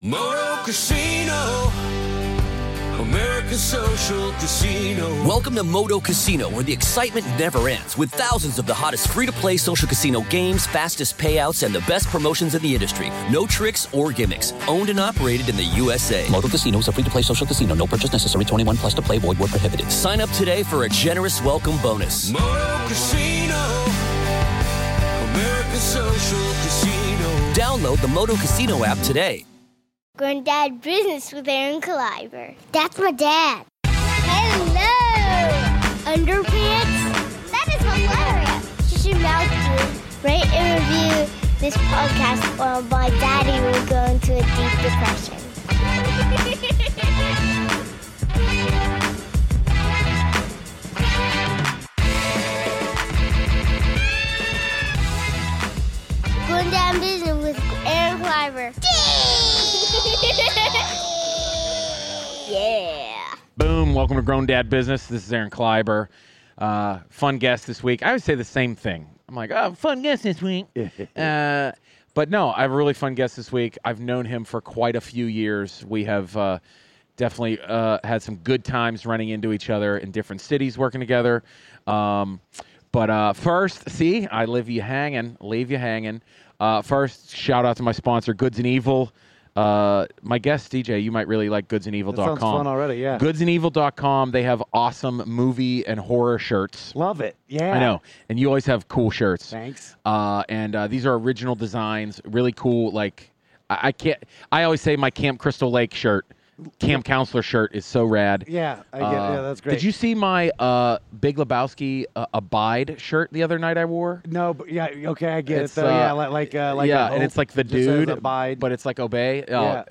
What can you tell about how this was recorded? at the Limestone Comedy Festival amidst a torrential downpour that interrupts the podcast and makes them runs for their lives.